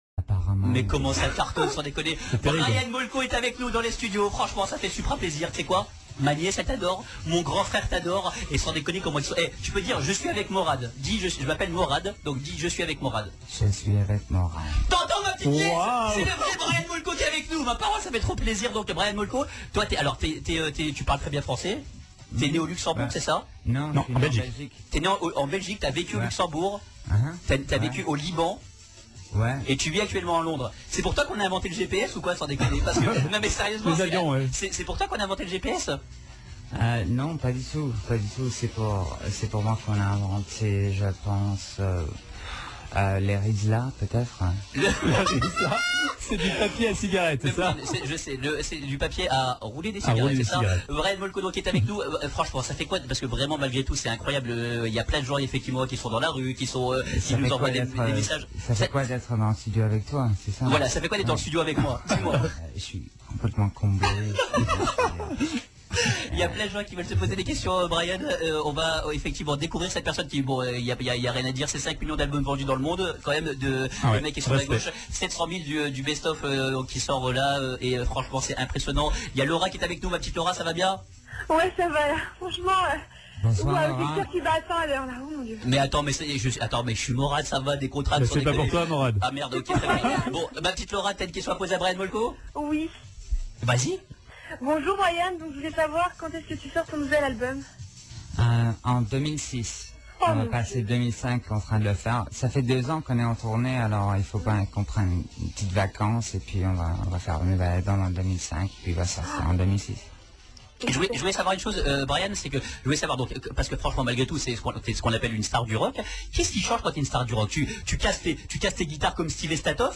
28 octobre 2004, Brian Molko invit� � l��mission du c�l�bre et parfois critiqu� animateur radio Maurad, une interview audio assez unique dans son genre et � ne surtout pas manquer�
Le 28 octobre 2004, Brian �tait convi� dans les locaux de la radio Europe 2 afin de participer � l��mission �Libert�, Egalit�, M.AU.RA.D� en direct entre 20h00 et 00h00. Une interview in�dite, gros zeste de folie, une bonne dose d�humour, des effluves alcoolis�es et le cocktail est�explosif !